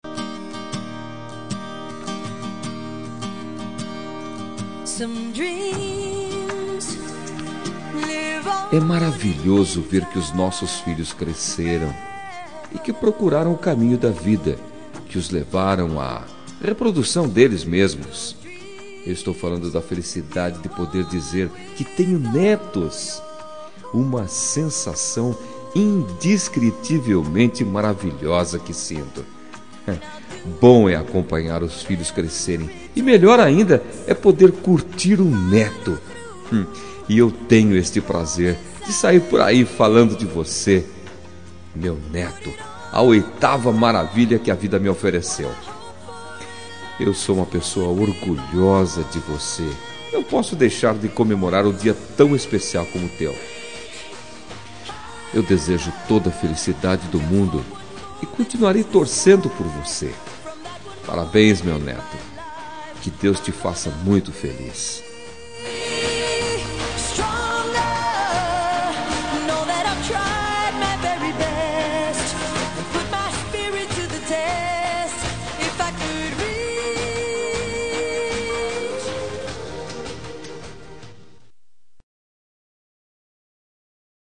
Aniversário de Neto – Voz Masculina – Cód: 131056